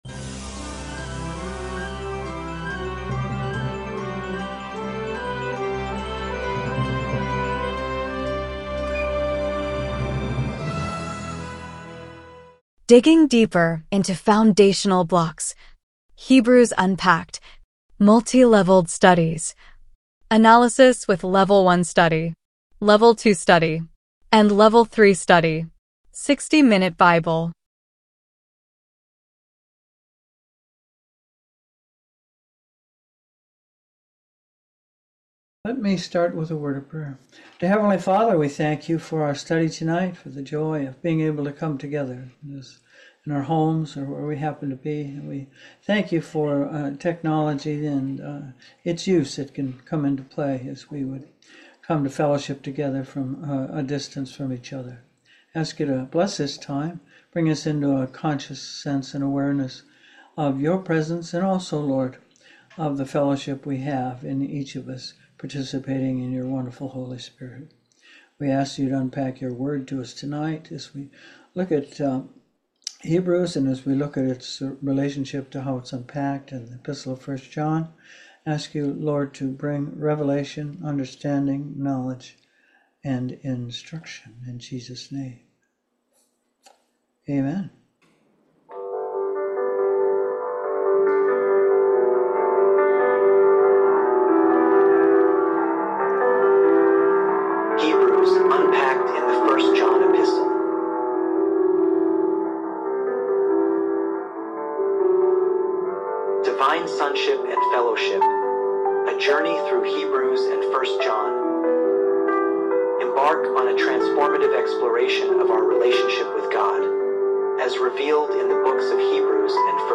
TBS 41 Unpacking Hebrews in1 John Live Group Study Audio.mp3